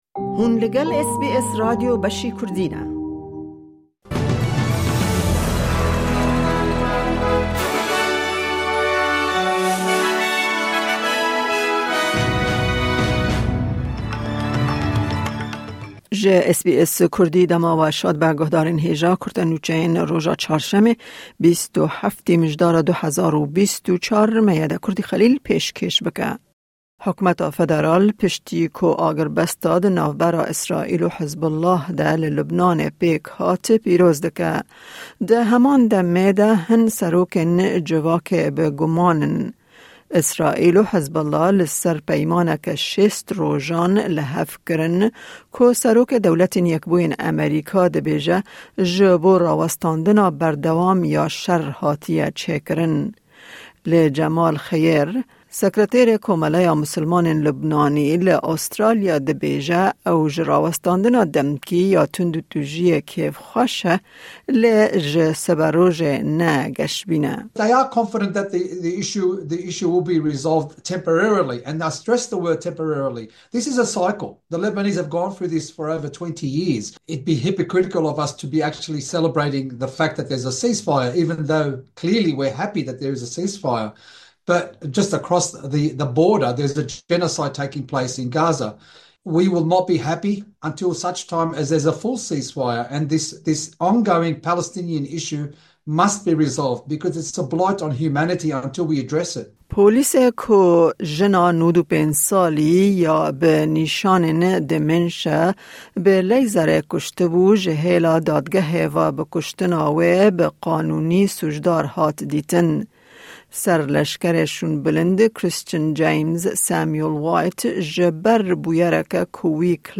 Kurte Nûçeyên roja Çarşemê 27î Mijdara 2024